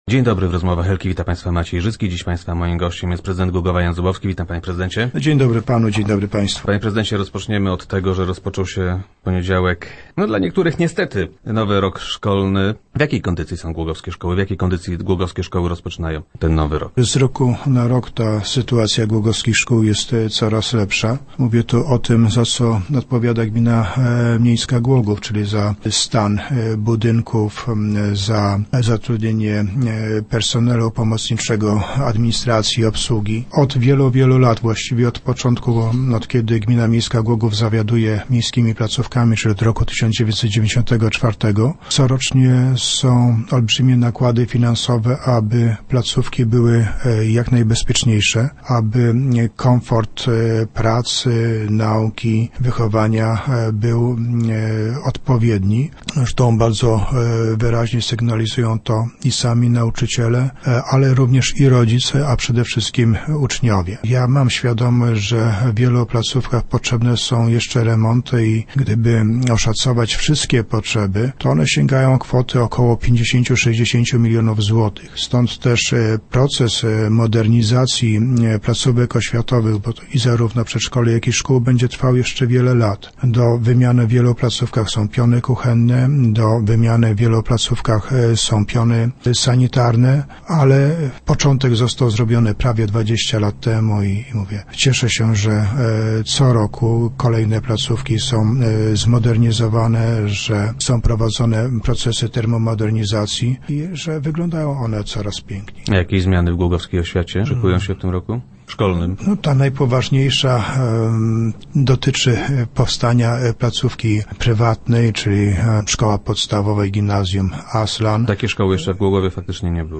- Wszystko wskazuje na to, że zrezygnujemy z podwyższania podatku od środków transportu. W ten sposób chcemy wesprzeć miejscowe firmy, które ten podatek płacą. W ubiegłym roku podjęliśmy podobną decyzję - powiedział prezydent Zubowski w środowych Rozmowach Elki.